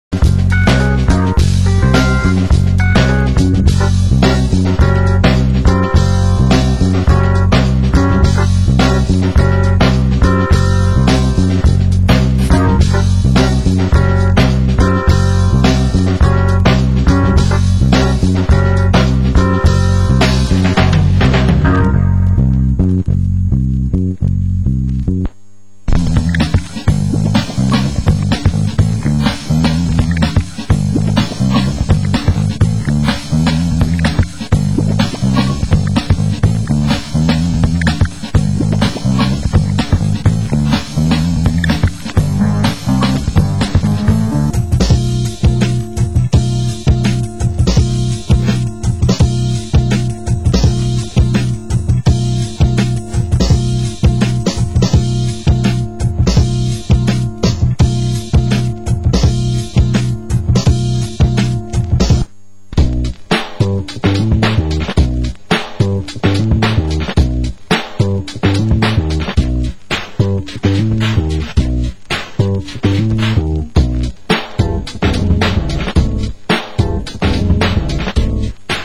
Genre: Break Beat